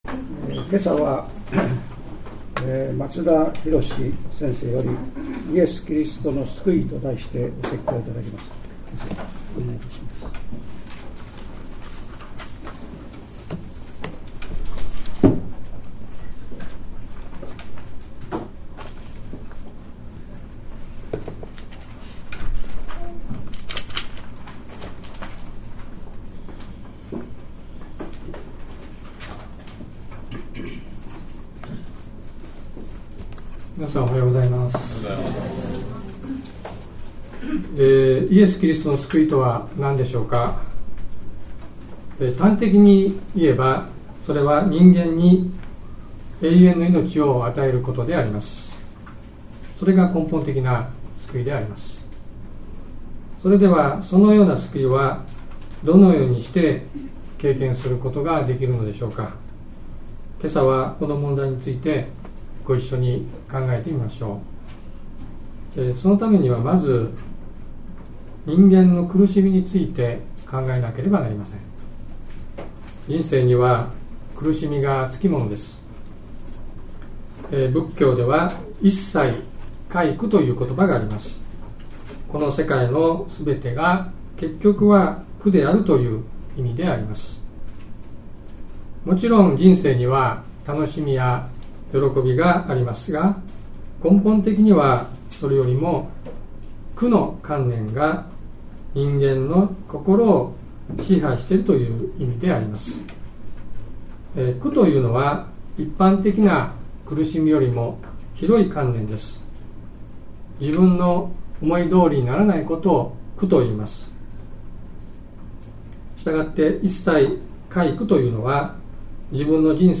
聖霊降臨節第11主日礼拝